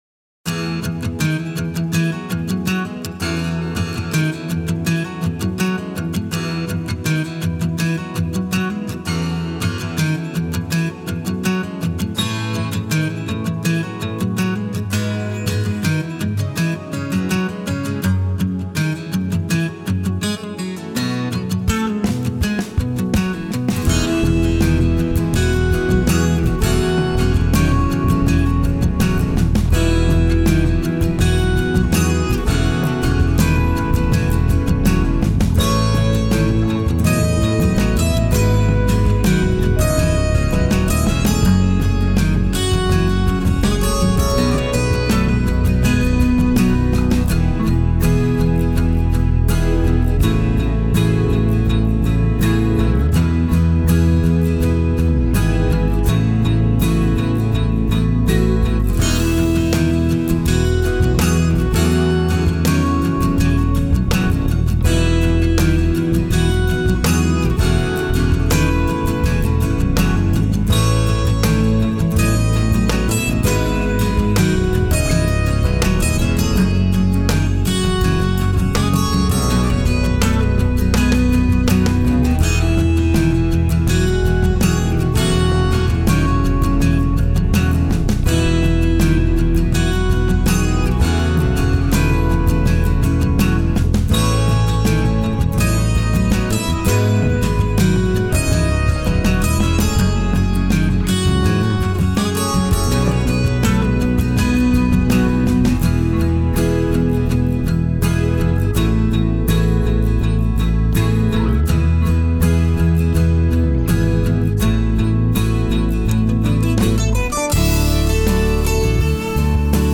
Acoustic 12-string guitar for Kontakt